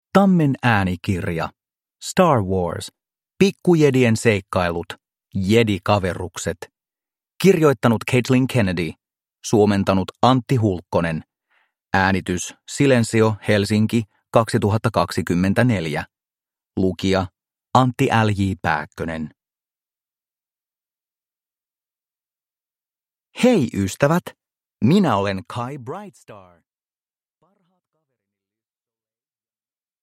Star Wars. Pikkujedien seikkailut. Jedikaverukset – Ljudbok